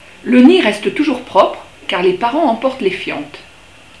Le cri de la mésange bleue